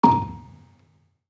Gambang-A#4-f.wav